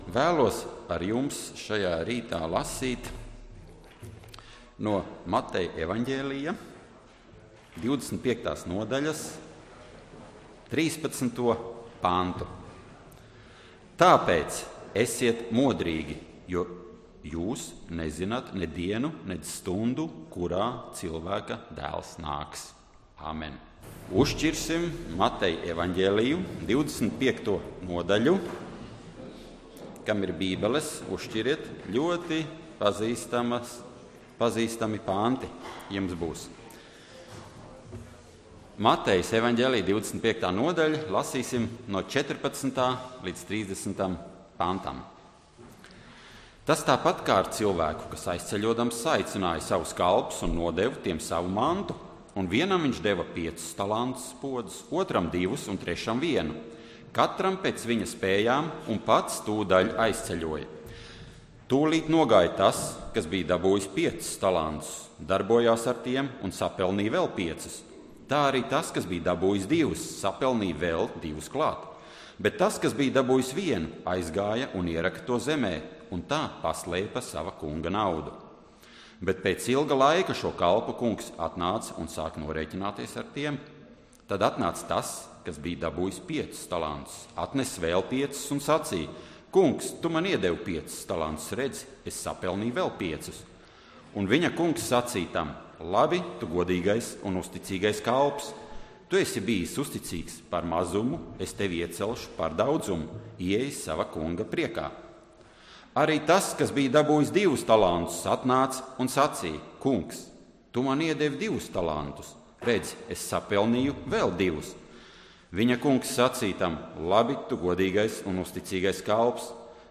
Dievkalpojums 14.02.2015: Klausīties
Svētrunas